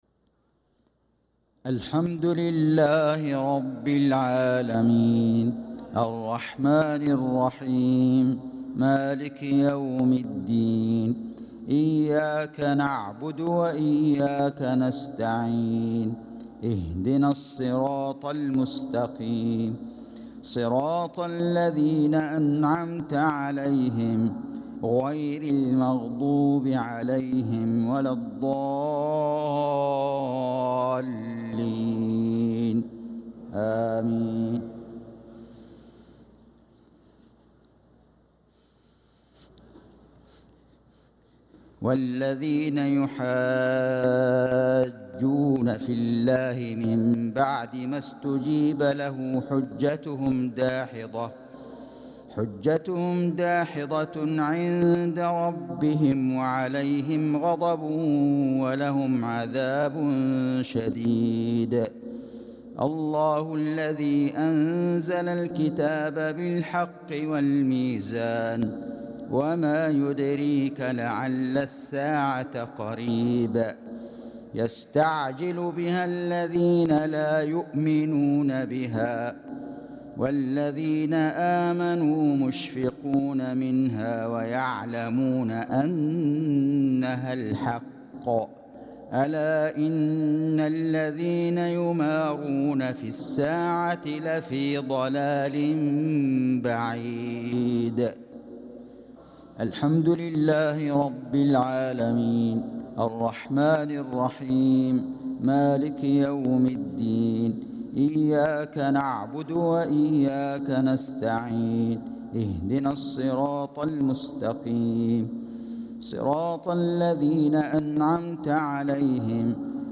صلاة المغرب للقارئ فيصل غزاوي 17 ذو الحجة 1445 هـ
تِلَاوَات الْحَرَمَيْن .